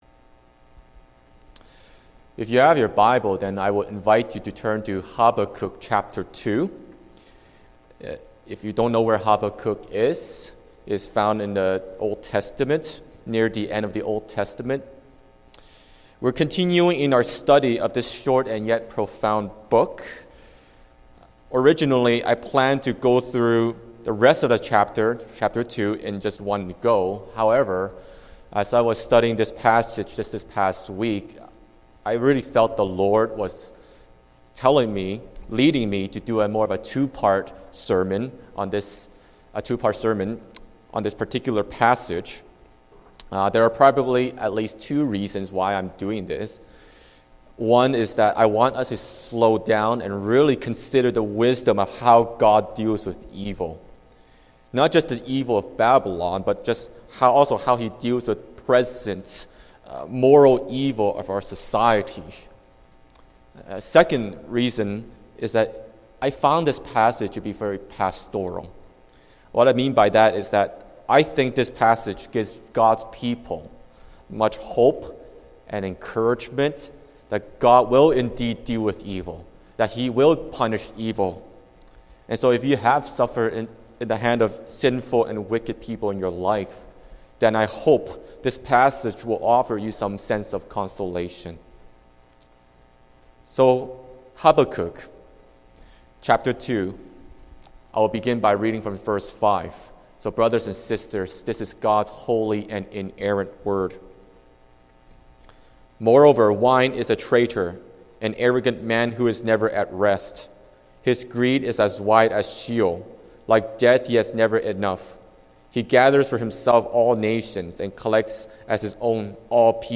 Sermons | Oakridge Baptist Church